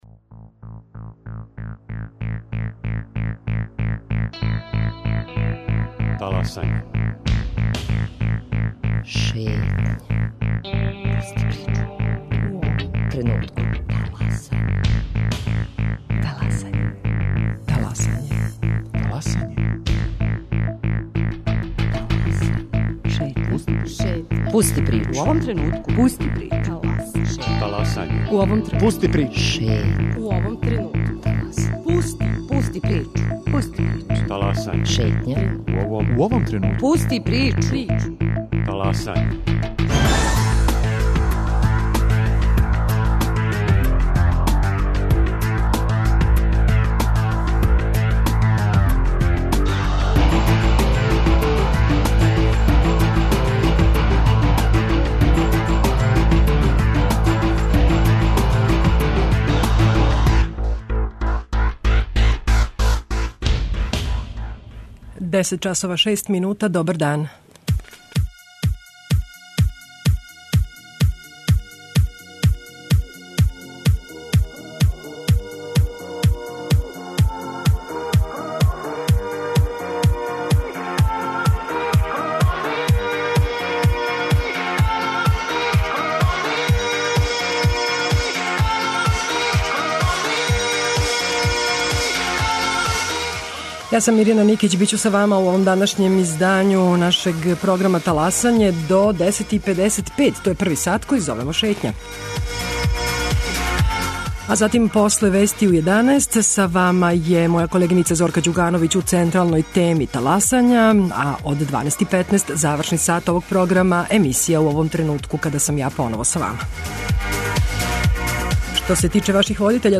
Гост у студију биће Дарко Главаш, начелник одељења за праћење, координацију и управљање пројектима Скупштине града, који председава петочланим телом које је задужено за издавање дозвола забављачима.